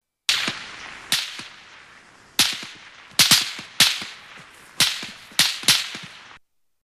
Звук выстрела пули в небо (щелкает)